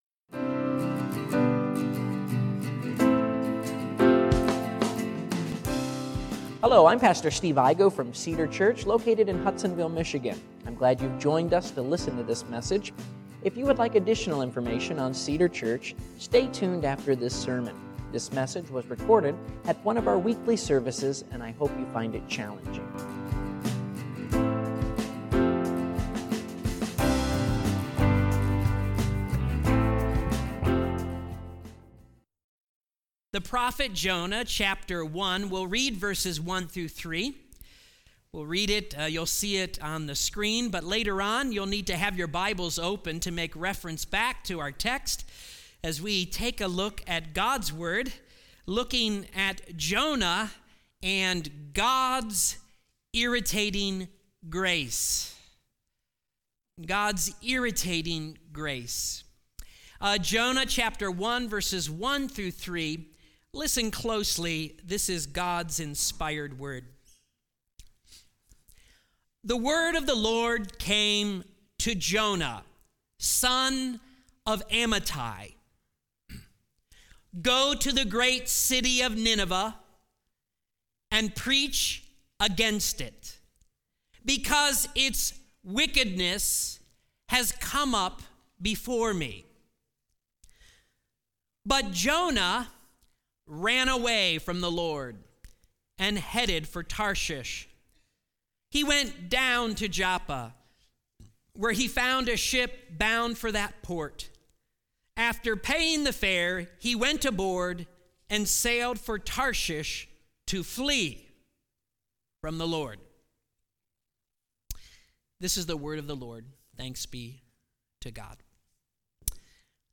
God's Irritating Grace - The Story of Jonah Current Sermon